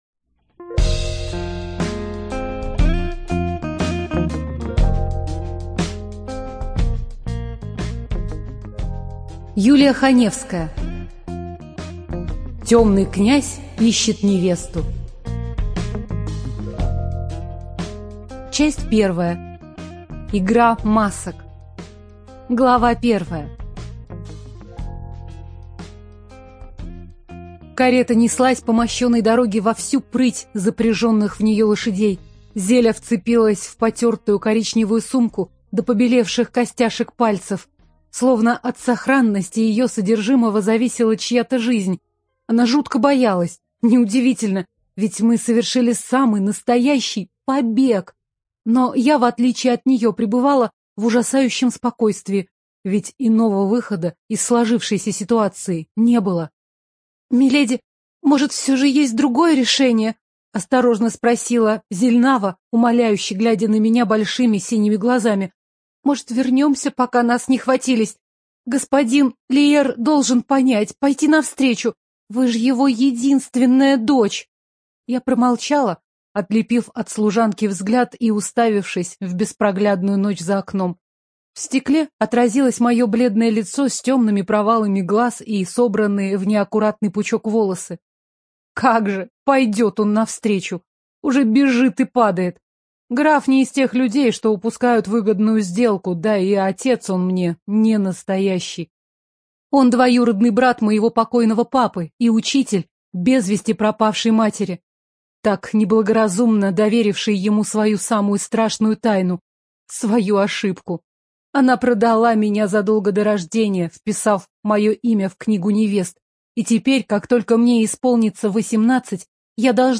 ЖанрЮмор и сатира, Фэнтези